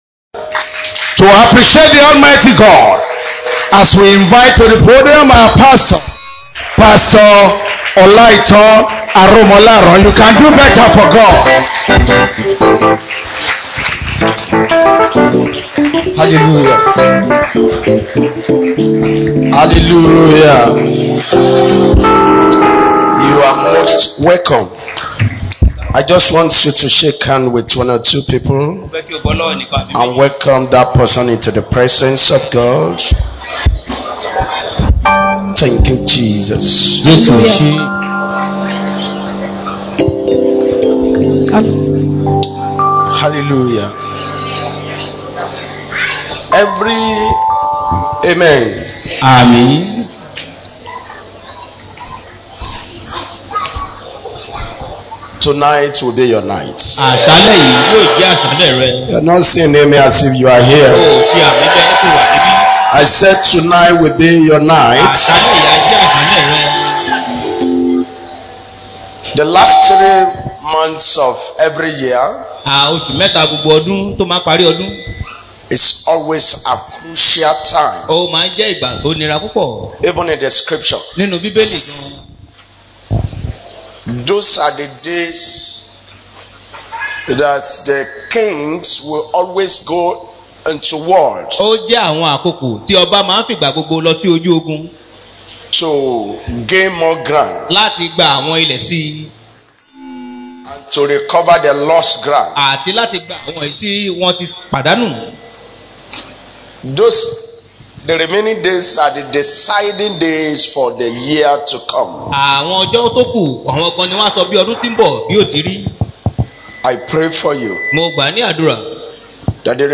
JACOB SERVICE is a monthly program of RCCG Miracle Centre Zone.
This is a very revealing sermon.